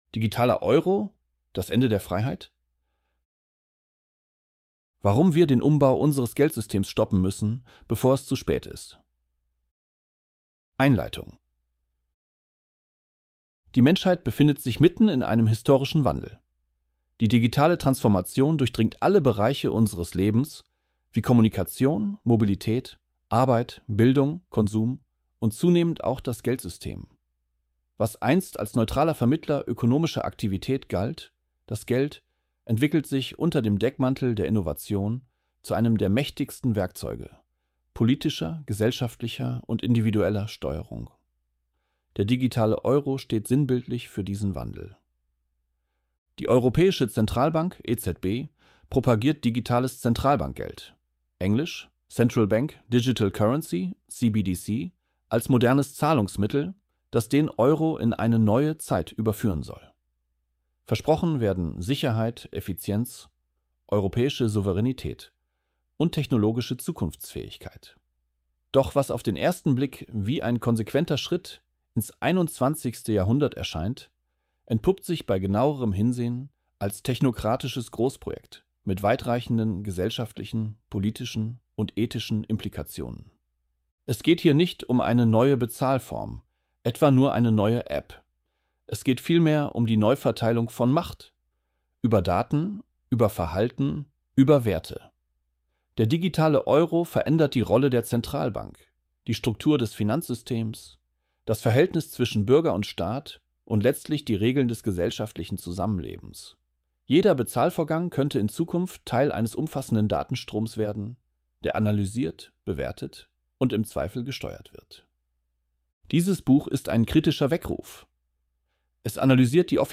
ElevenLabs_Digitaler_Euro.mp3